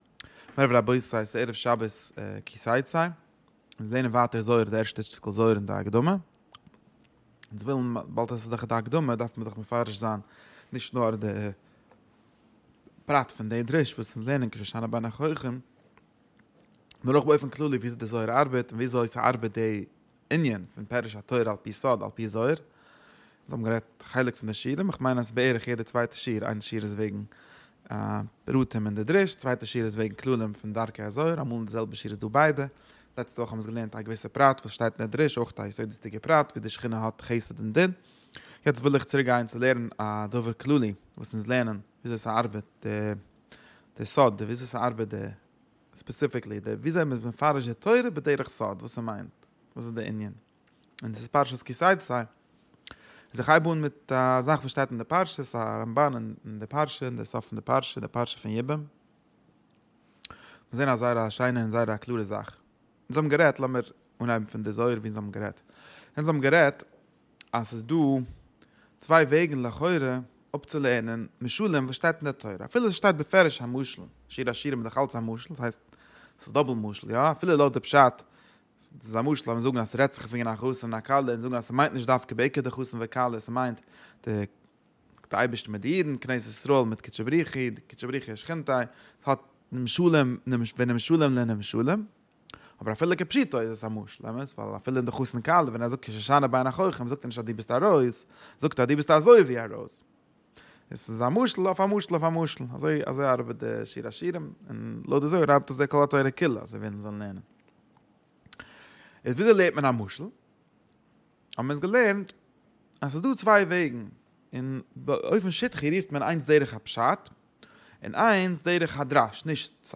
שיעור שבועי